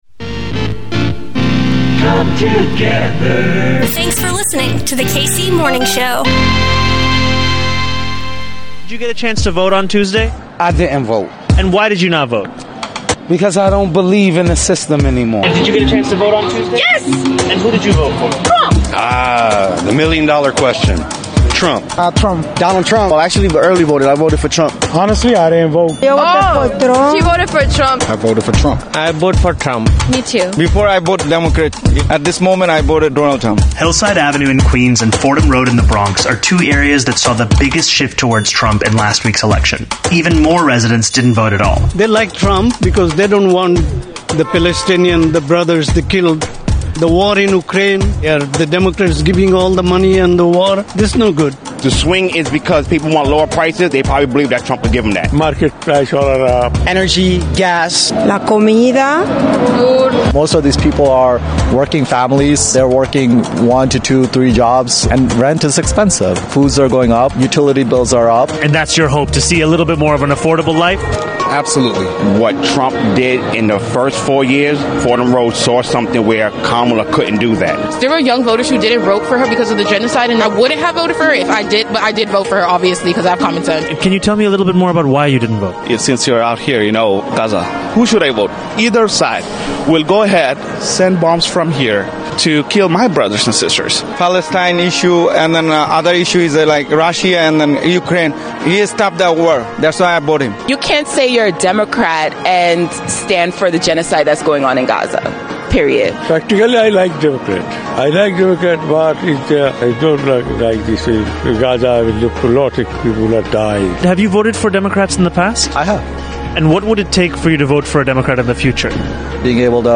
Happy Tuesday from YOUR KC Morning Show!